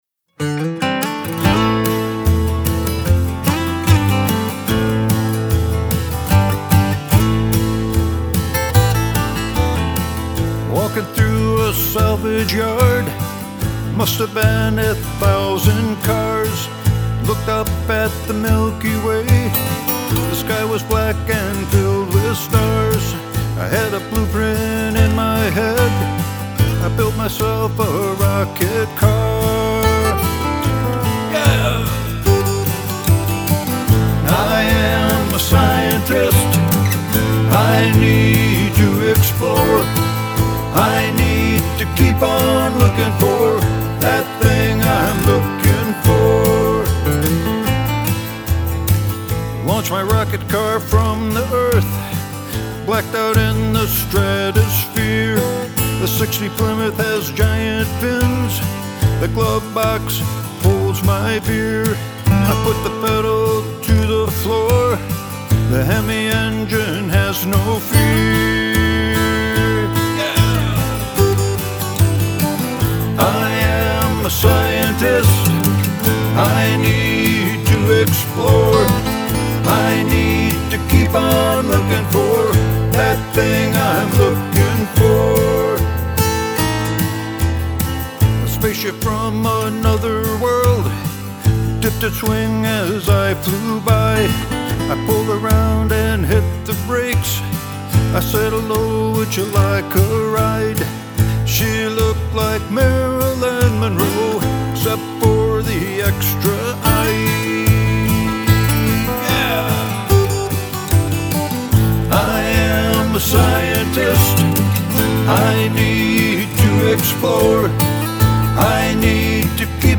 I also recorded it in my basement office also using a Zoom Q2n-4k.